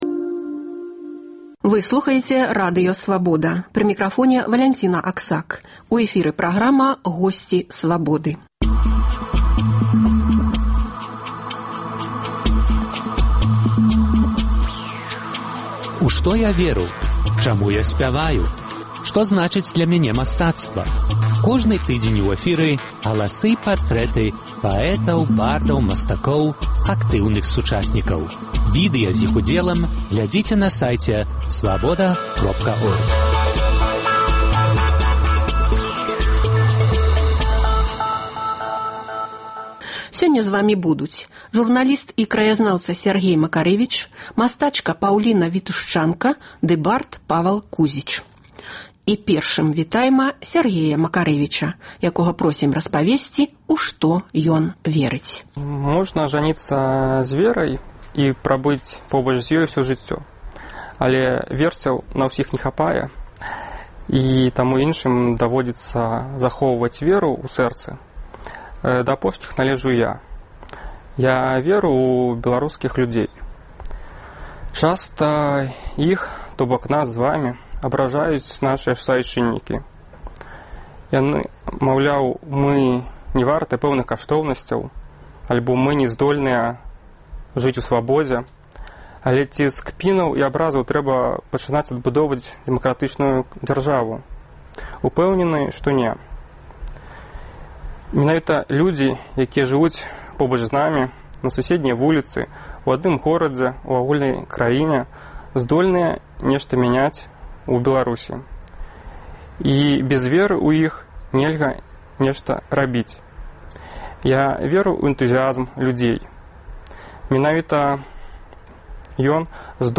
Галасы і партрэты паэтаў, бардаў, мастакоў, актыўных сучасьнікаў. У студыі